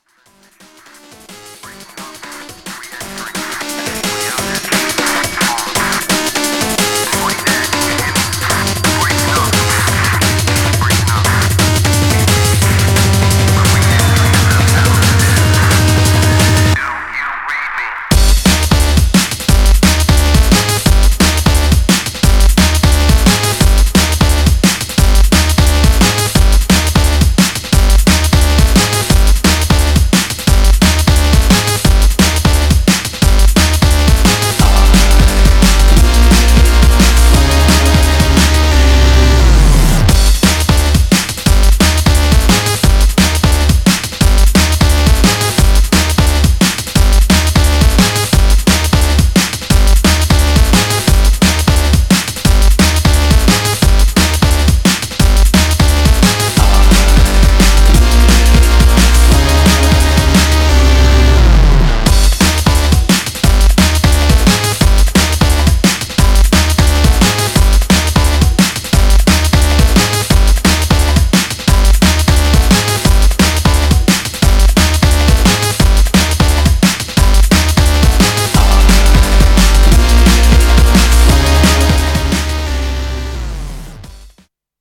Styl: Drum'n'bass Vyd�no